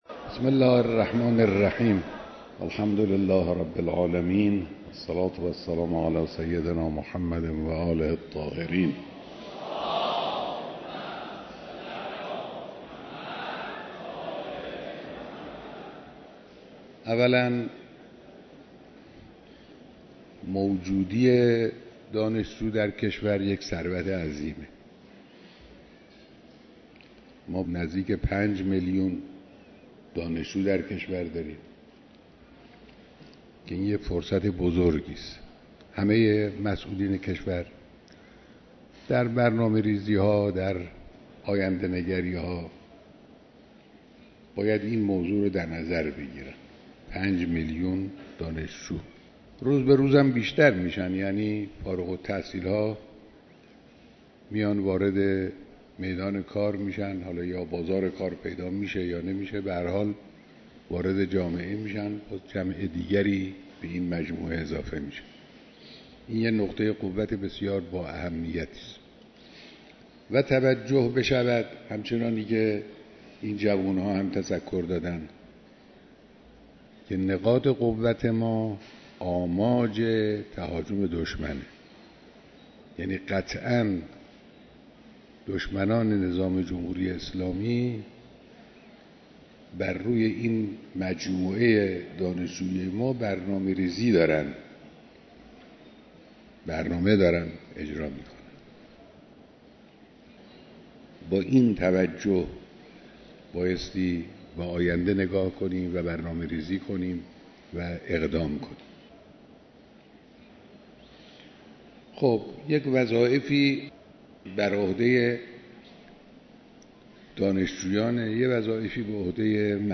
بیانات در دیدار جمعی از دانشجویان و نمایندگان تشکل‌های دانشجویی - قسمت دوم